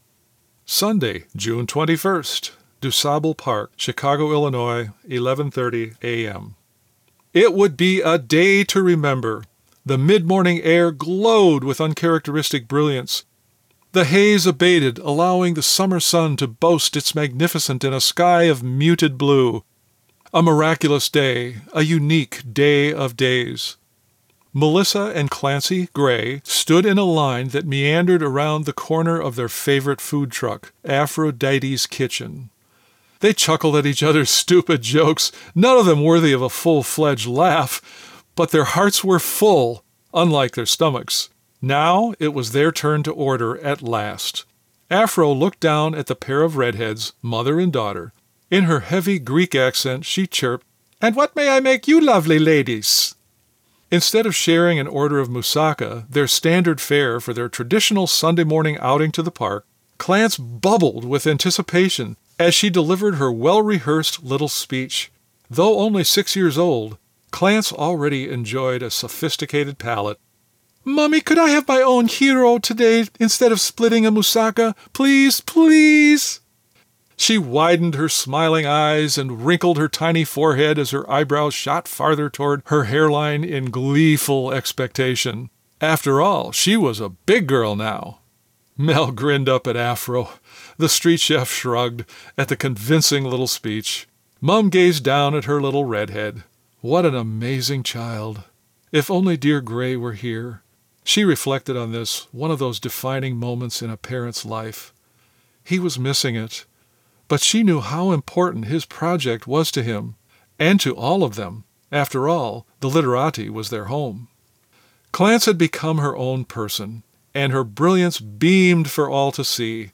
Grinnin’ in “the studio” (inside the motorhome) cookin’ up audiobooks AND podcasts… wherever we park the rig, while still finding time to “retire” and suck the juice out of every single day (and out of my newish MacBook Pro that’s getting the workout of its life)!